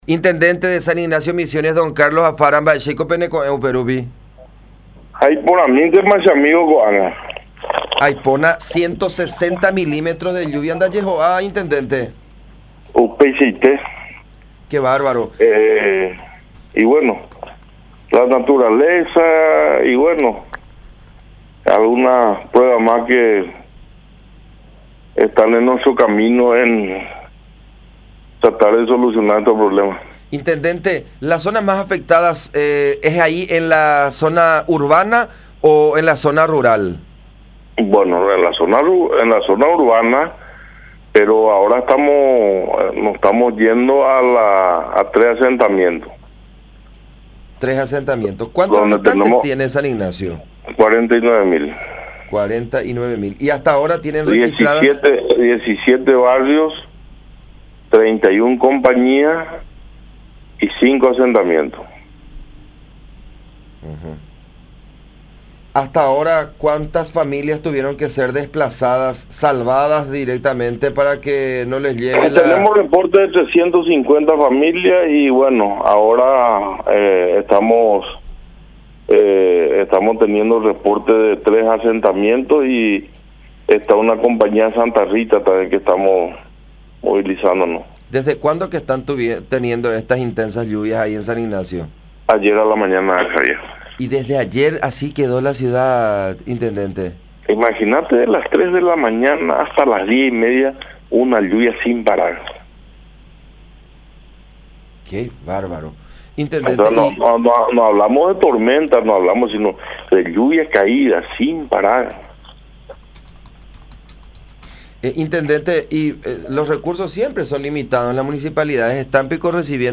El intendente de San Ignacio, Carlos Afara, explicó que la zona más afectada del municipio es la urbana, donde hay mayor cantidad de habitantes.
“Ayer a la mañana cayó lluvia sin parar durante tres horas. Imaginate lo que es. Fue impresionante”, expresó el jefe comunal en contacto con La Unión, manifestando su satisfacción por no haber tenido que registrar ninguna víctima fatal a causa del fenómeno meteorológico.
04-INTENDENTE-CARLOS-AFARA.mp3